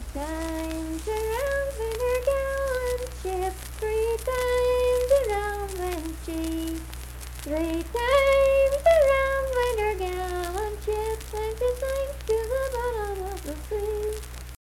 Unaccompanied vocal music
Verse-refrain 1(4). Performed in Strange Creek, Braxton, WV.
Voice (sung)